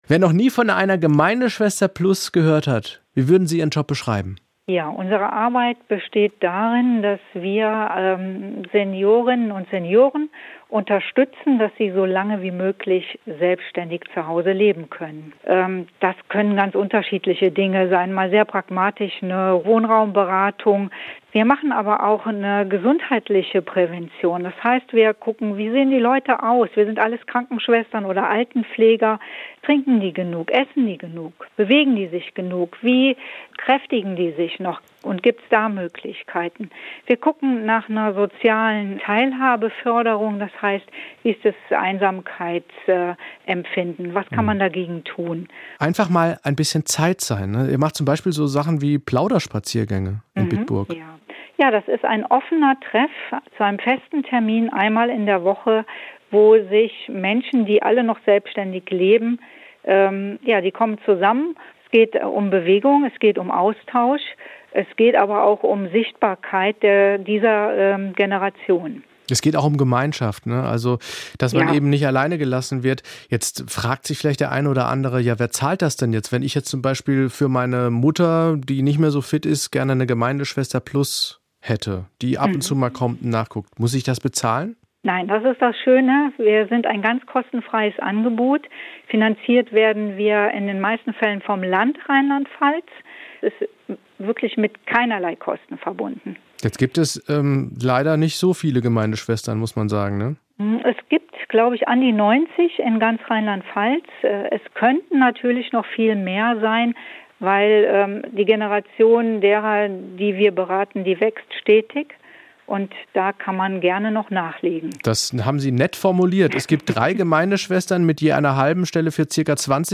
Mehr SWR1 RPL Interviews